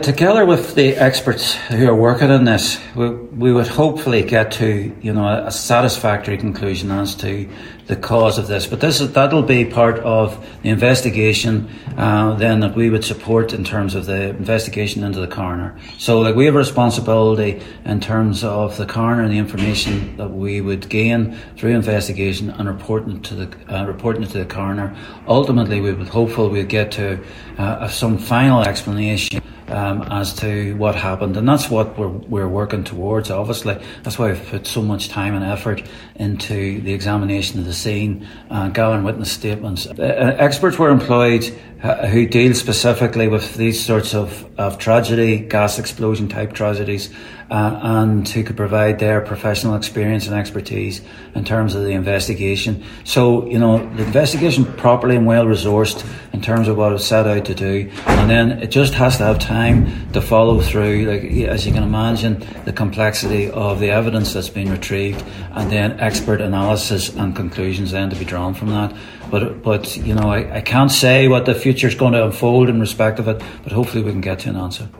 Garda Commissioner Drew Harris (Centre) at today’s meeting of the Donegal Joint Policing Committee.
Garda Commissioner Drew Harris says while the outcome is uncertain, they are hopeful they will come to a satisfactory conclusion: